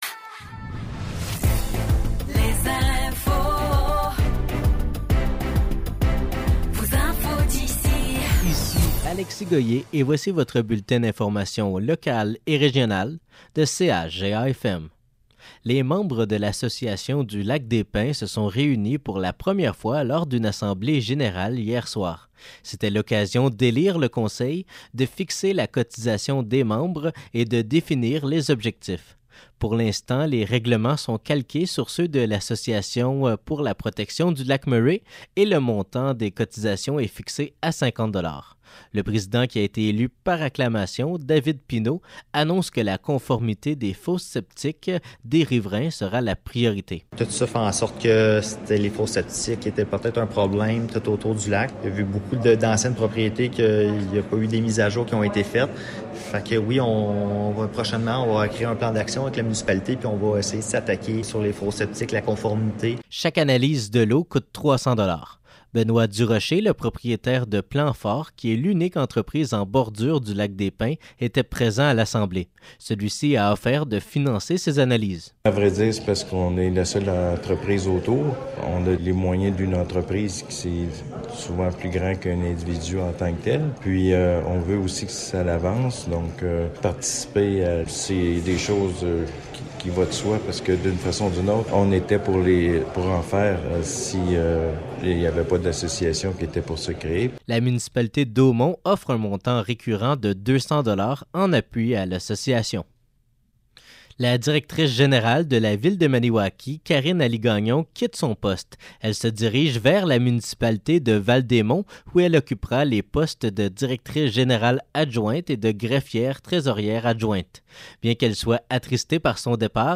Nouvelles locales - 17 novembre 2023 - 12 h